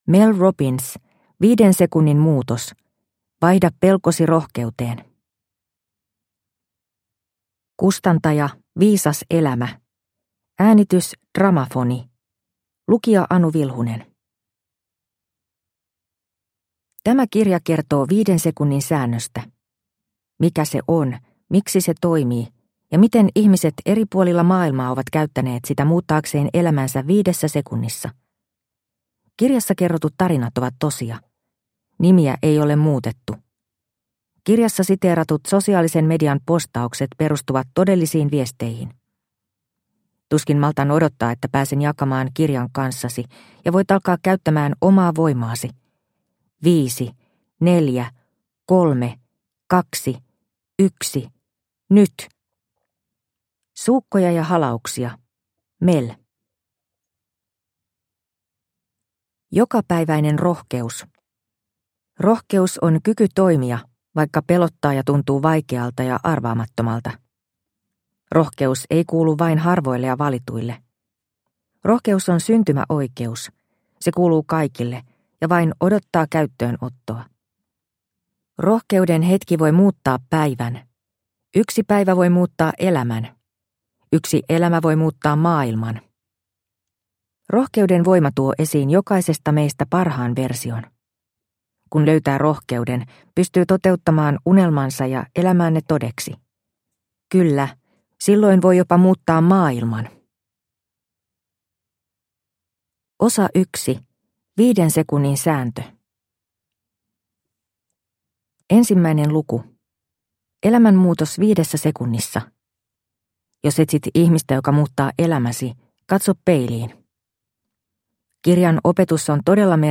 5 sekunnin muutos – Ljudbok – Laddas ner